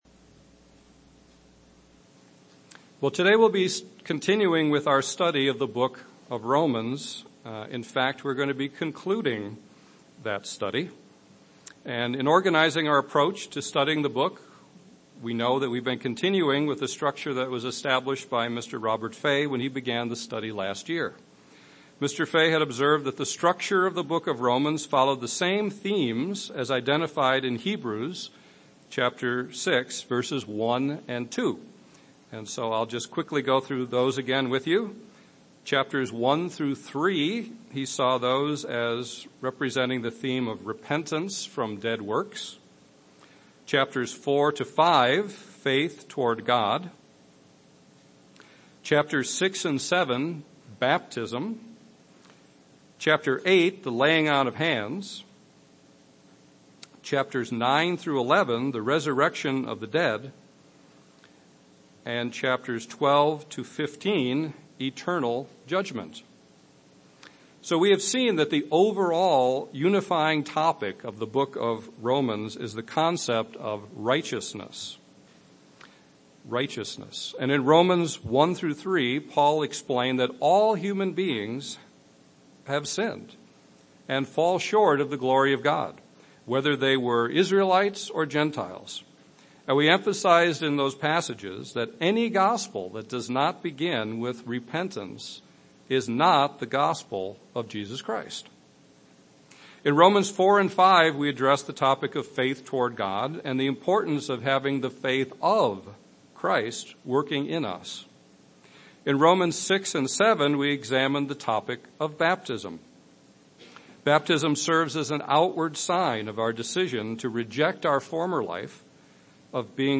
The concluding Bible Study in the Book of Romans series. Romans Chapters 12 through 15 are examined, with special emphasis on the theme of eternal judgment.
Given in Chicago, IL